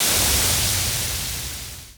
Waterspray 2.wav